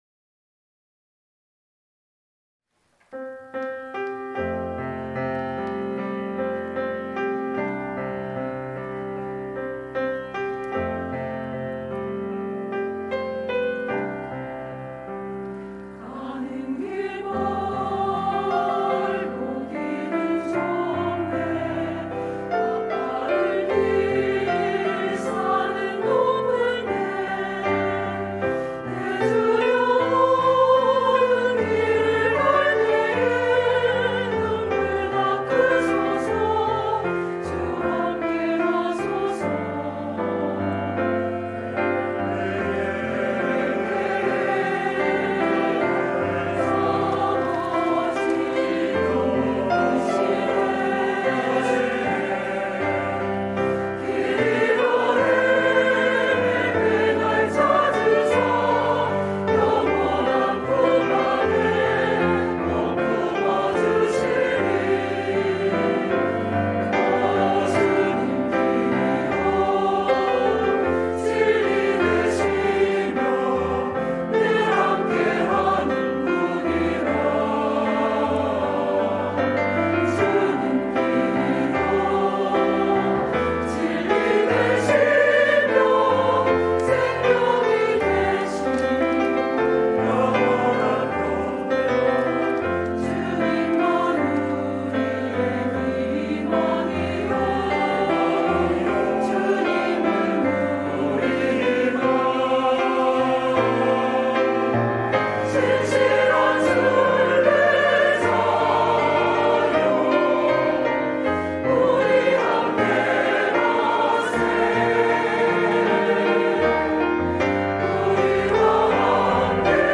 영은 3.3 예배